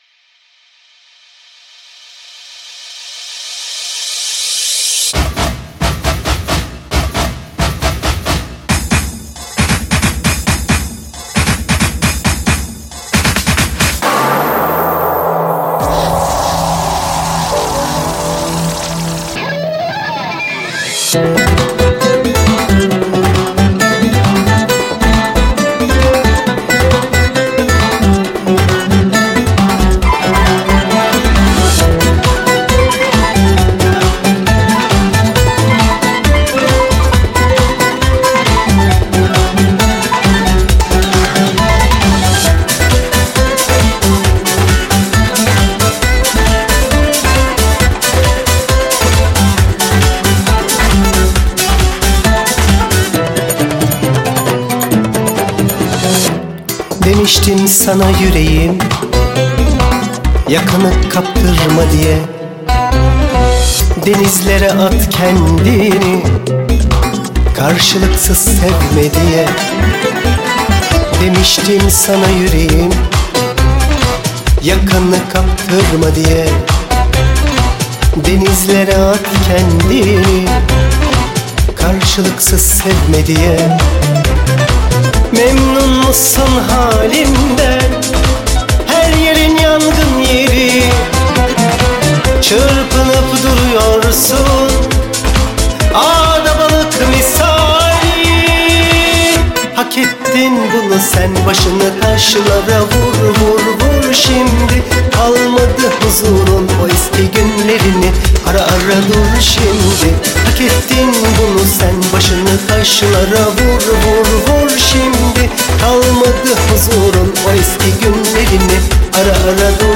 Eser Şekli : Pop Fantazi